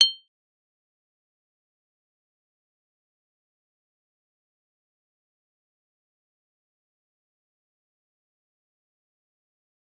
G_Kalimba-G7-f.wav